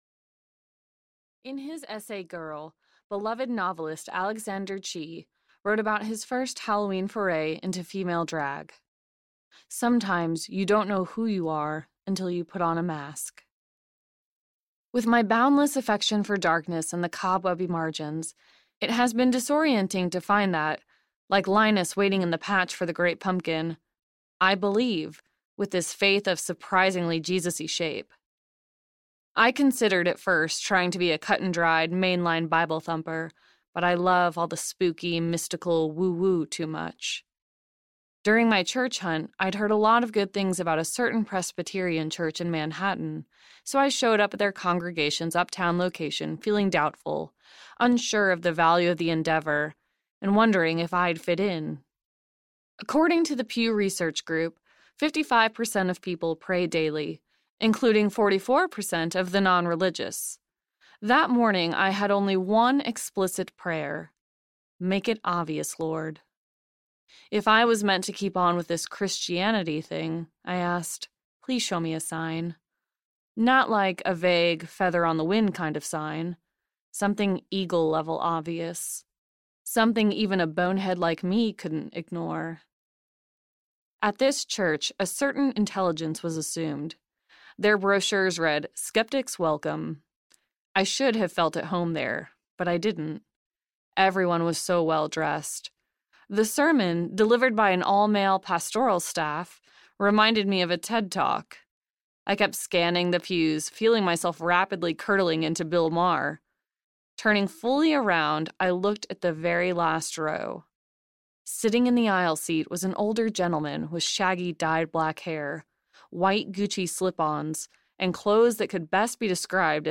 Narrator
6.67 Hrs. – Unabridged